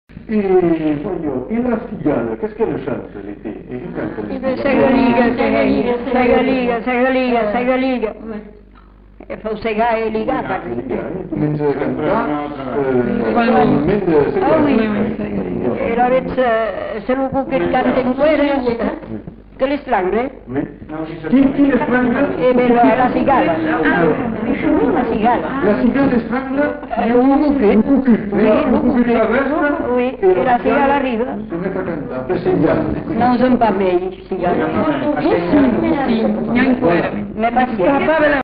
Aire culturelle : Bazadais
Lieu : Villandraut
Genre : forme brève
Effectif : 1
Type de voix : voix de femme
Production du son : récité
Classification : mimologisme